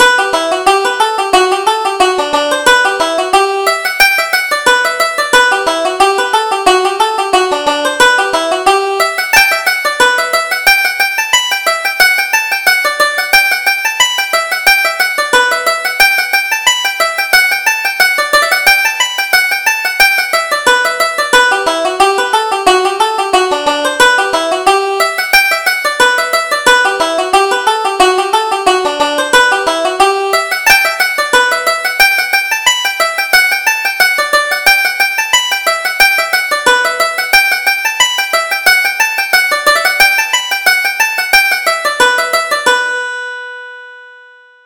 Reel: Tie the Ribbons